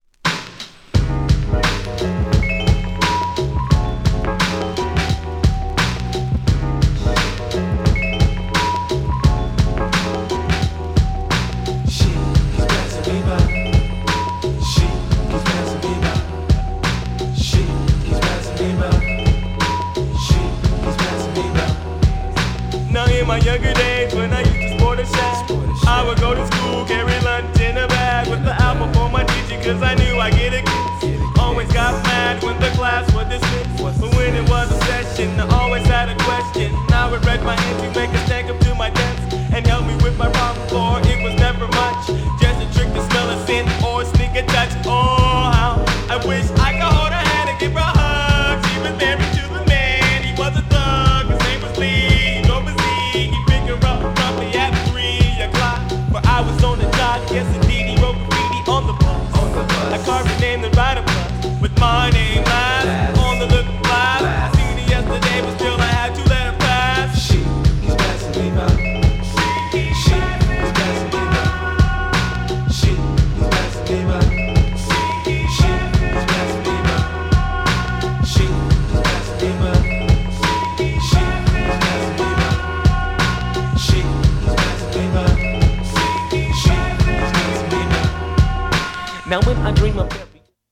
のJAZZYなREMIXも収録!!!
GENRE Hip Hop
BPM 76〜80BPM